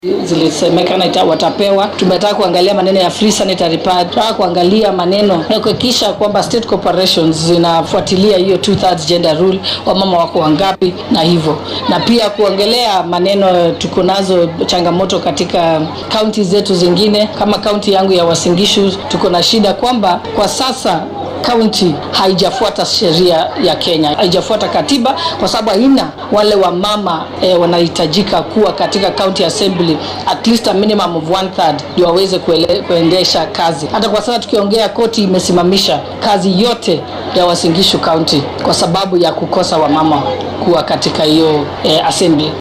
Waxaa uu shirkan ka socda xarunta Bomas ee magaalada Nairobi. Shirweynahan oo ay isugu yimaadeen madaxda ugu sarreysa xisbiga iyo sidoo kale qaybaha kala duwan ee haweenka hoggaanka u ah xisbiga UDA ayaa ujeedadiisu tahay sidii loo xoojin lahaa hoggaaminta dumarka iyo dhiirigelinta haweenka si ay ugu tartamaan jagooyinka sare ee hoggaaminta.